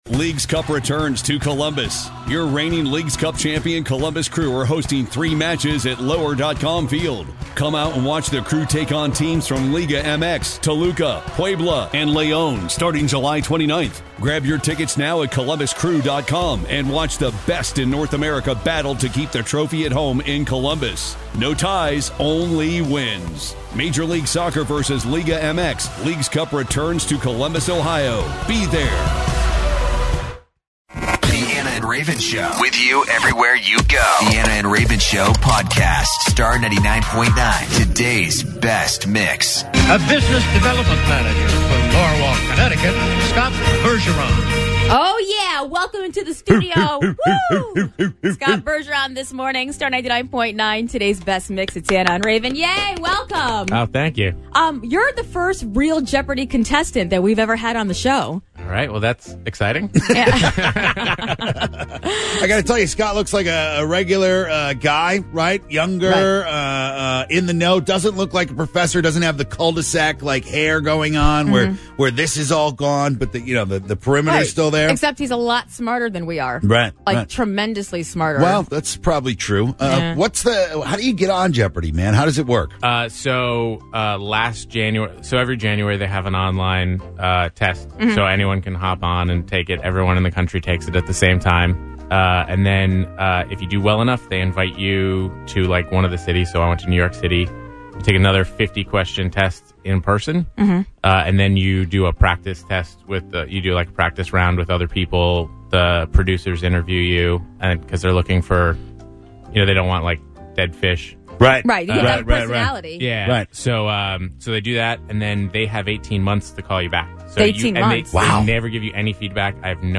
in studio today for a battle of the wits